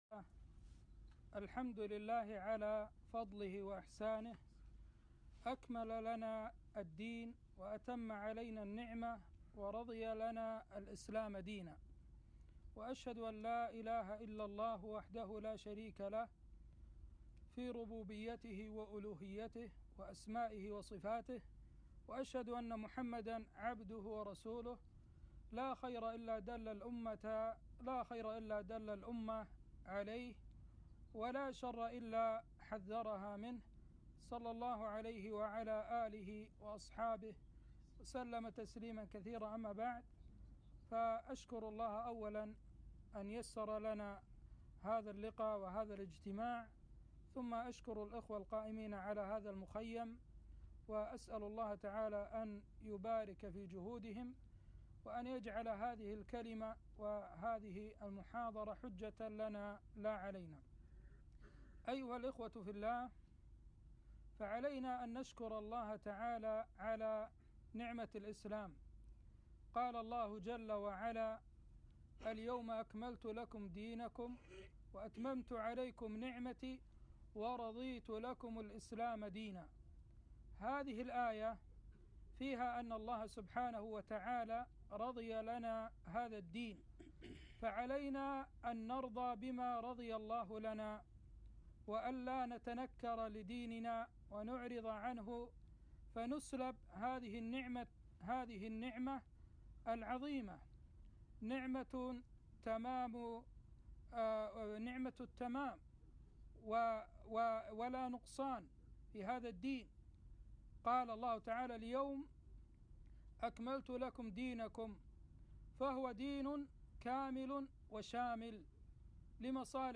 محاضرة - التحذير من البدع